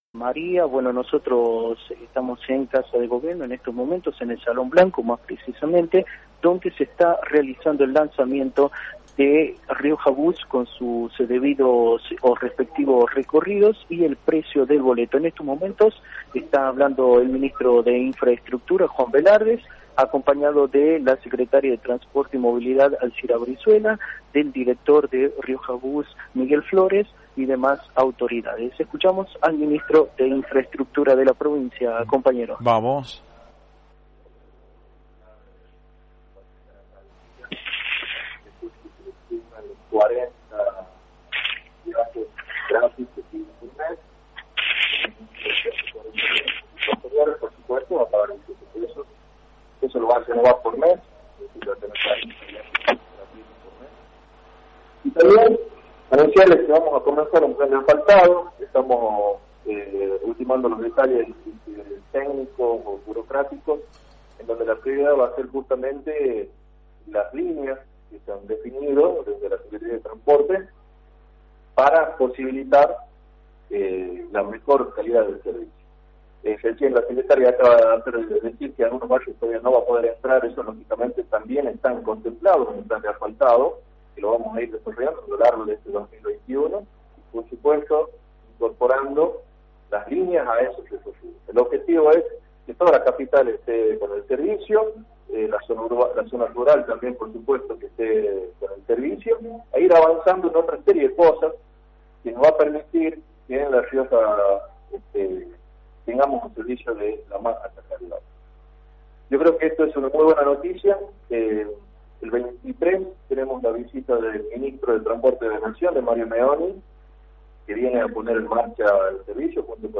El Salon Blanco de la Casa de gobierno de La Rioja, fue el epicentro de la conferencia de prensa.
Audio de la conferencia